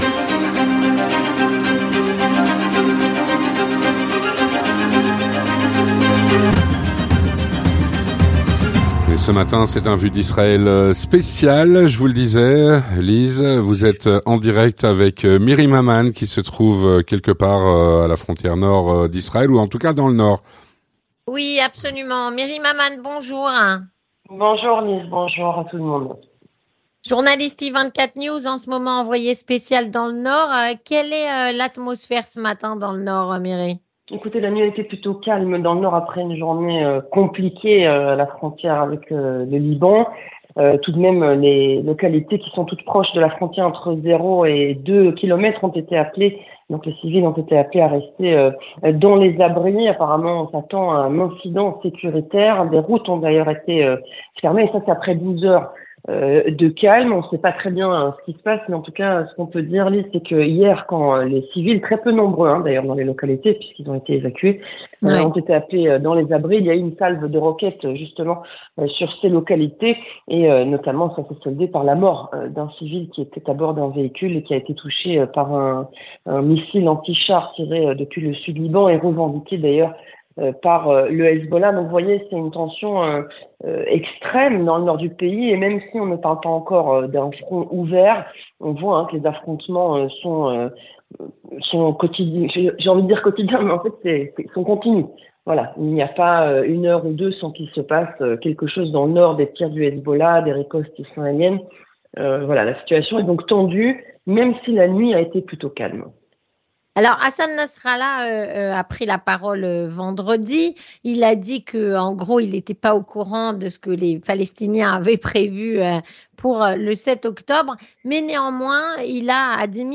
La situation tendue dans le Nord d'Israël. on en parle avec notre invitée qui est sur place.